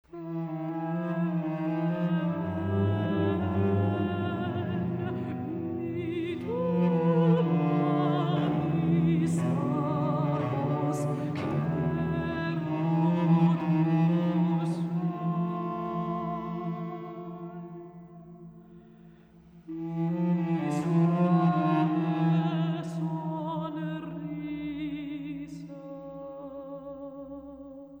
Für Sopran und Violoncello
Neue Musik
Vokalmusik
Duo
Sopran (1), Violoncello (1)